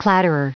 Prononciation du mot clatterer en anglais (fichier audio)
Prononciation du mot : clatterer